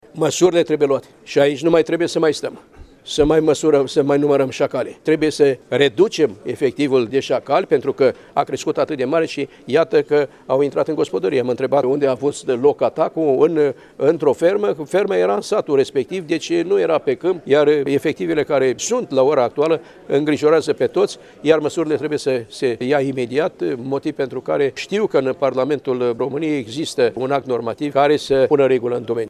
Iaşi – Ministrul Agriculturii, Petre Daea, s-a întîlnit cu fermieri şi agricultori din judeţ